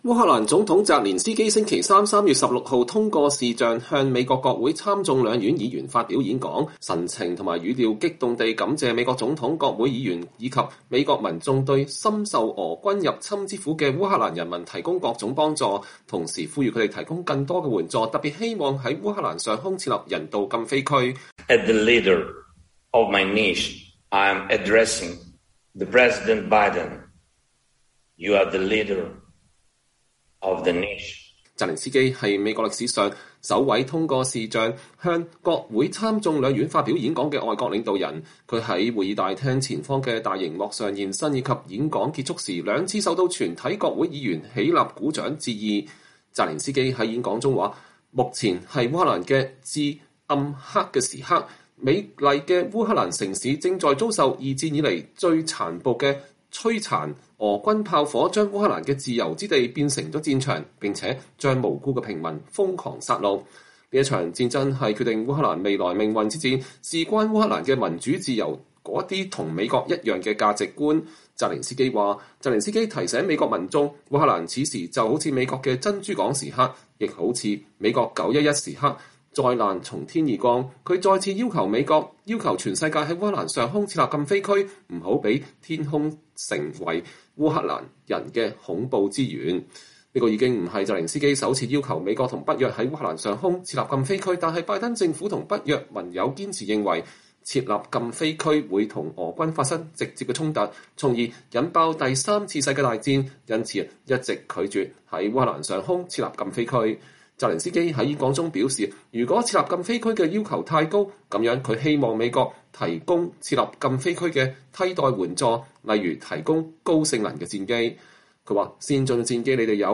烏克蘭總統澤連斯基（Volodymyr Zelenskiy）星期三（3月16日）通過視頻向美國國會參眾兩院議員發表演講，神情和語調激動地感謝美國總統、國會議員以及美國民眾對深受俄軍入侵之苦的烏克蘭人民提供的各種幫助，同時呼籲他們提供更多的援助，特別希望在烏克蘭上空設立人道禁飛區。
他在會議大廳前方的大熒幕上現身以及演講結束時，兩次受到全體國會議員起立鼓掌的致意。